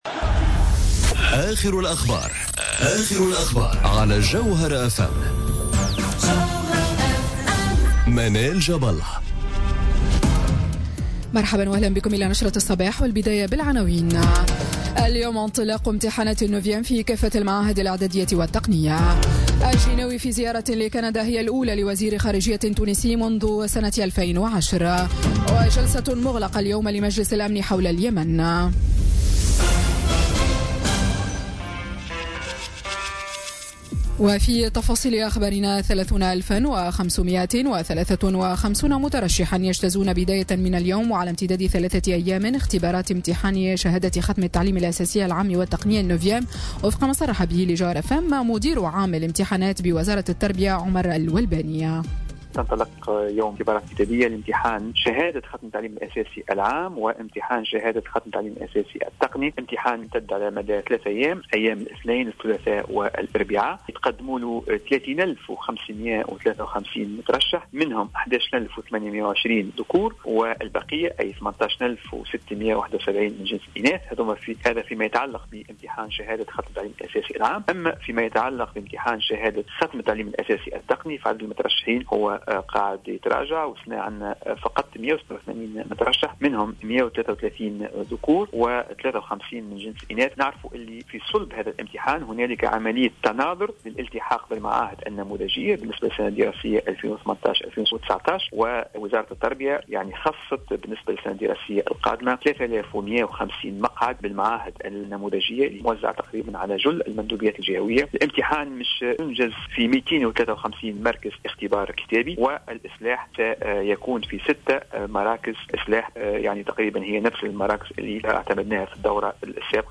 نشرة أخبار السابعة صباحا ليوم الإثنين 18 جوان 2018